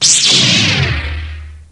saberSwitchOn2.wav